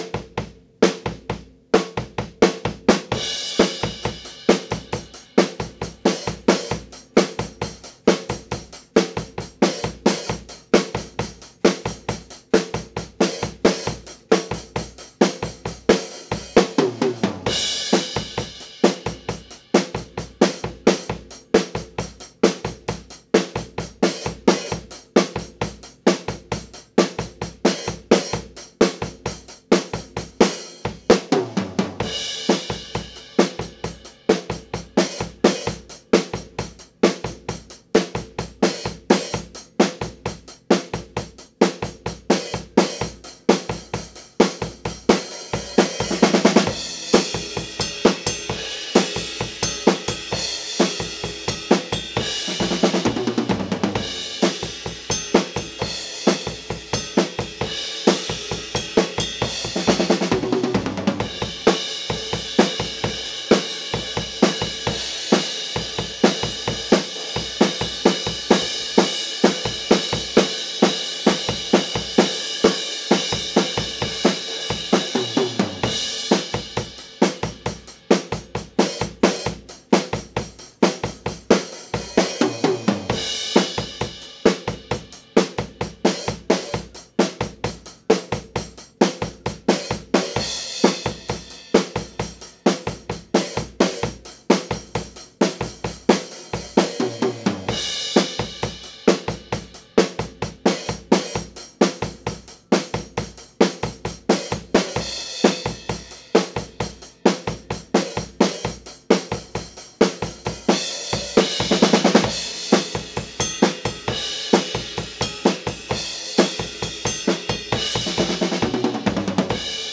Der große Drum-Mic-Shootout